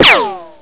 Ricochet.ogg